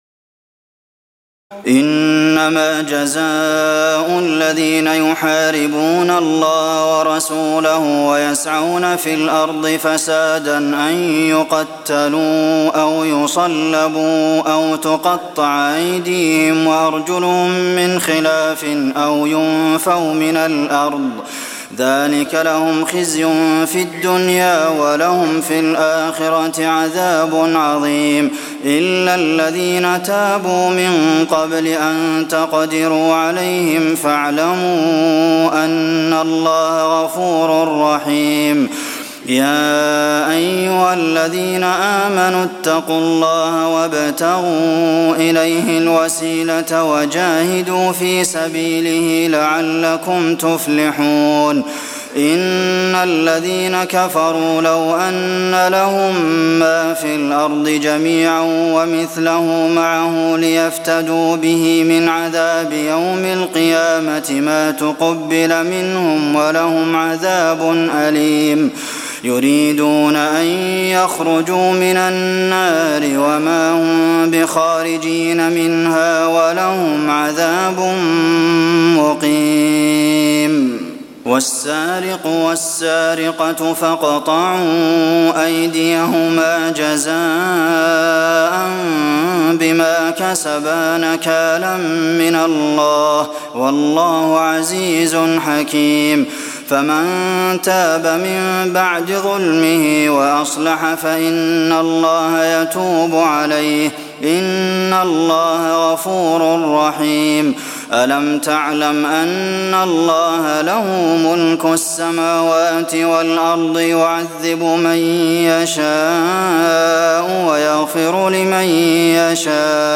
تراويح الليلة السادسة رمضان 1423هـ من سورة المائدة (33-81) Taraweeh 6 st night Ramadan 1423H from Surah AlMa'idah > تراويح الحرم النبوي عام 1423 🕌 > التراويح - تلاوات الحرمين